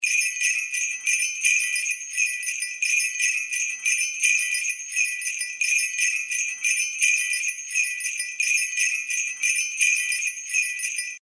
sleigh-bells-sound_14301.mp3